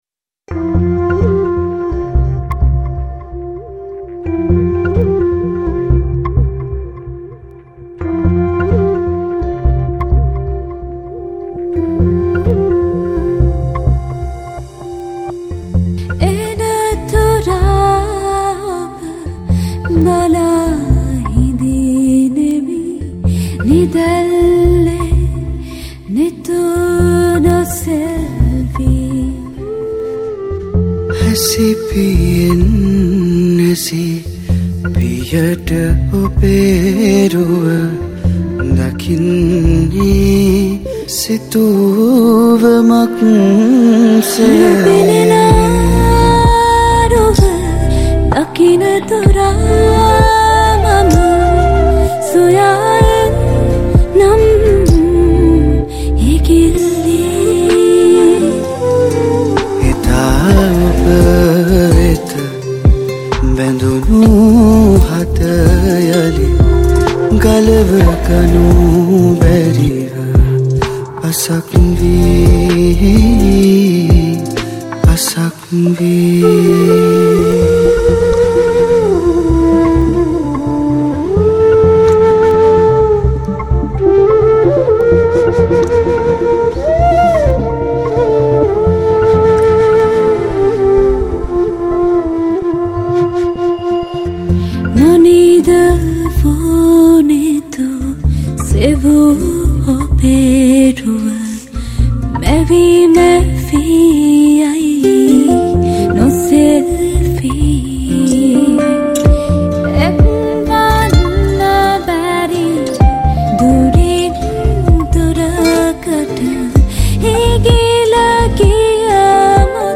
Vocals
Flute
Guitars